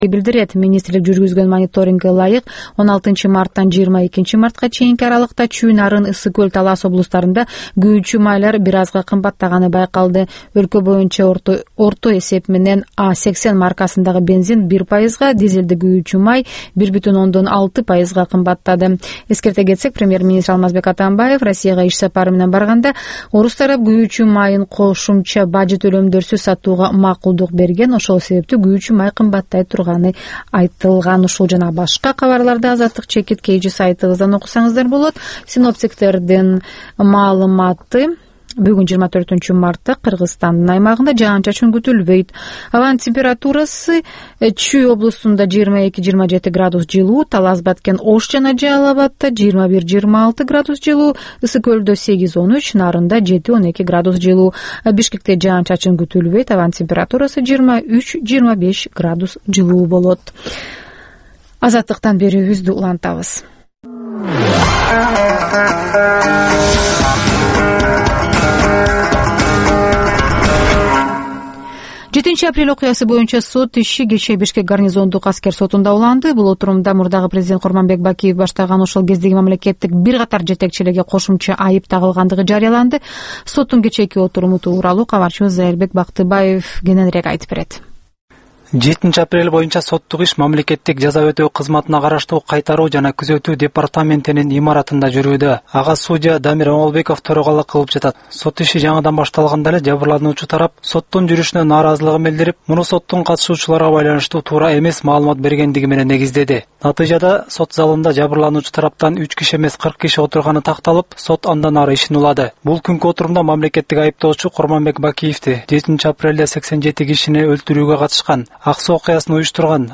Бул таңкы үналгы берүү жергиликтүү жана эл аралык кабарлар, ар кыл орчун окуялар тууралуу репортаж, маек, талкуу, баян, күндөлүк басма сөзгө баяндама жана башка берүүлөрдөн турат. "Азаттык үналгысынын" бул таңкы берүүсү Бишкек убакыты боюнча саат 08:00ден 08:30га чейин обого чыгарылат.